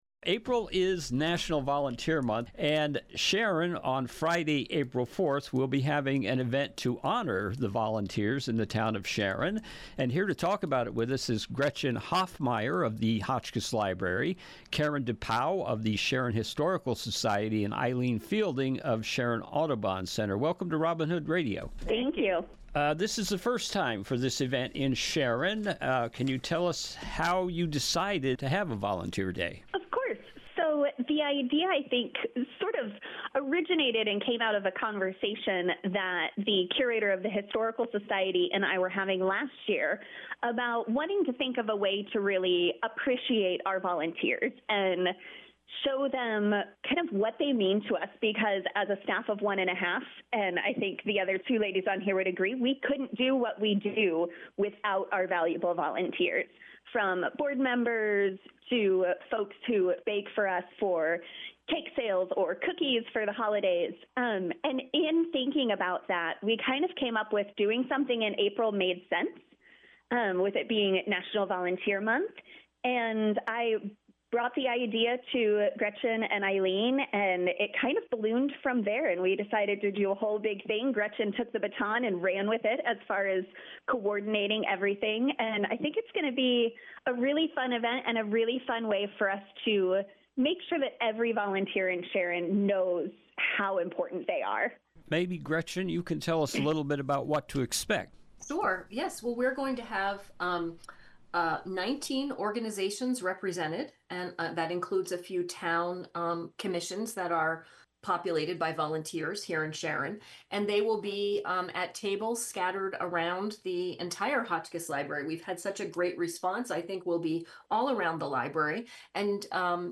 Interview: Sharon Volunteer Recognition and Recruitment Event- April 4, 2025